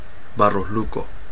Ääntäminen
Vaihtoehtoiset kirjoitusmuodot Barros Luco Ääntäminen Chile Tuntematon aksentti: IPA: /ˈbaros ˈluko/ Haettu sana löytyi näillä lähdekielillä: espanja Käännöksiä ei löytynyt valitulle kohdekielelle.